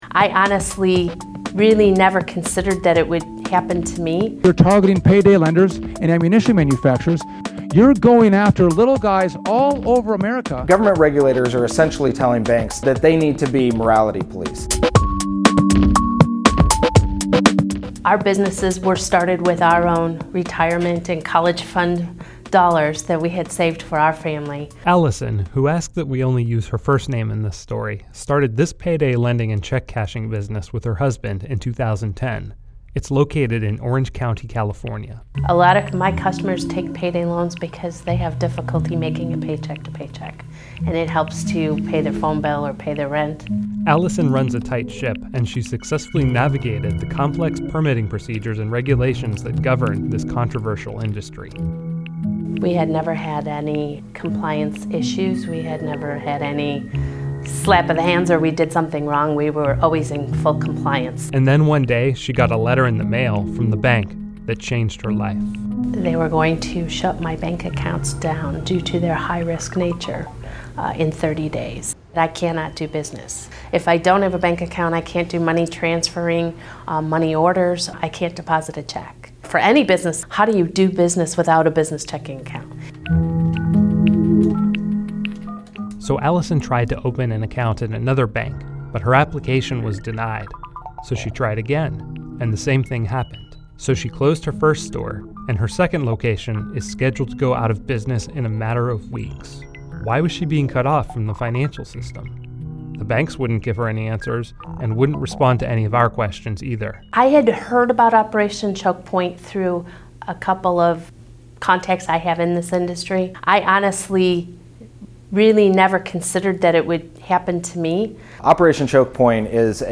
Reason TV profiled two business owners who believe they've been targets of Choke Point and its legacy: a payday lender in Southern California and a hookah seller in North Carolina.